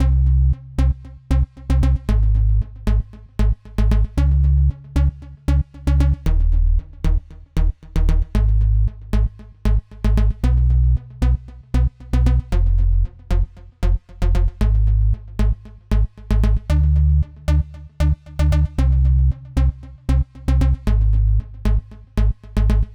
115 BPM Beat Loops Download